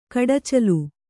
♪ kaḍacalu